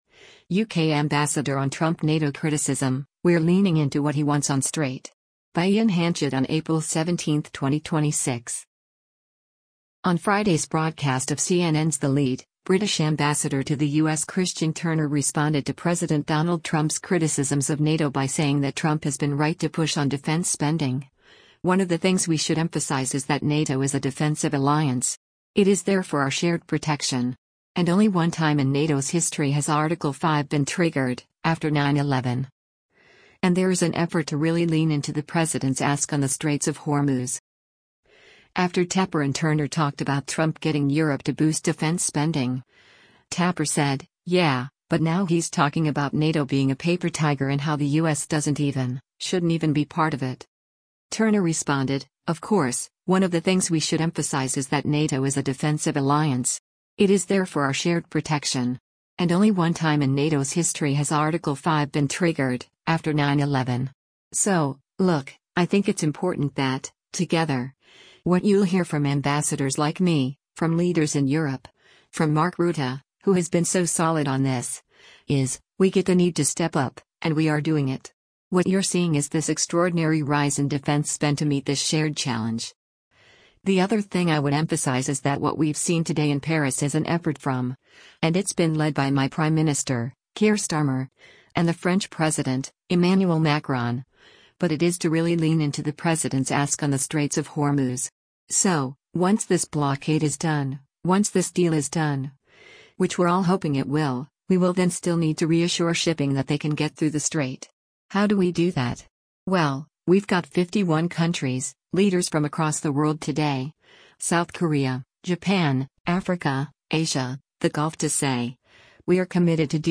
On Friday’s broadcast of CNN’s “The Lead,” British Ambassador to the U.S. Christian Turner responded to President Donald Trump’s criticisms of NATO by saying that Trump has been right to push on defense spending, “one of the things we should emphasize is that NATO is a defensive alliance. It is there for our shared protection. And only one time in NATO’s history has Article 5 been triggered, after 9/11.” And there is an effort “to really lean in to the President’s ask on the Straits of Hormuz.”